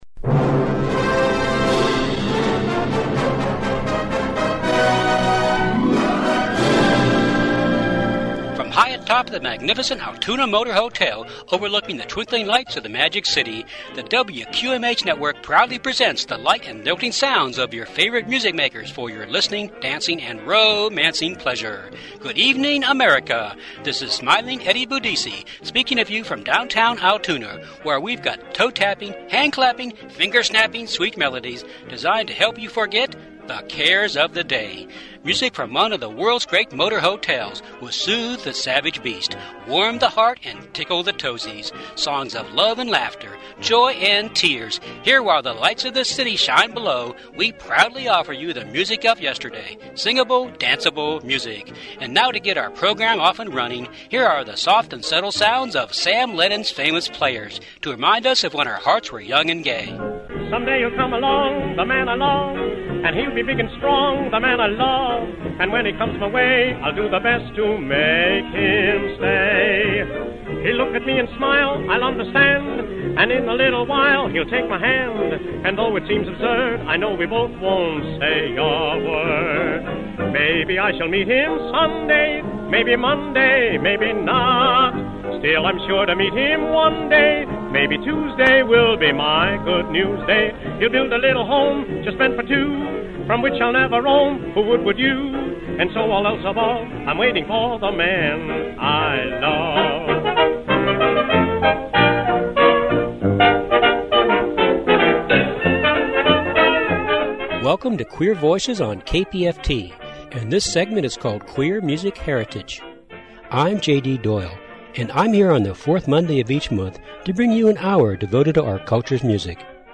I'm covering it all, and roughly breaking it down into the areas of the cross-vocals of the 30's, music from the UK, Blues, Female Impersonators, Novelties, 50's women and r&b, and some very queer & campy songs.